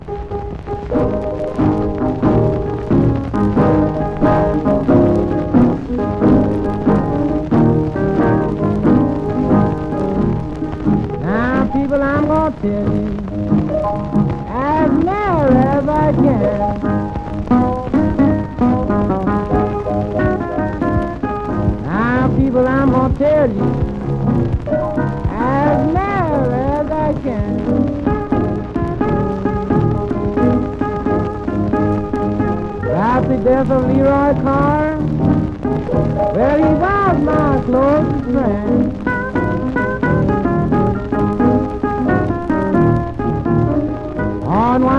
洒落っ気や都会的と形容されるブルースの音。
Blues　Netherlands　12inchレコード　33rpm　Stereo